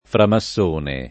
framassone [ frama SS1 ne ]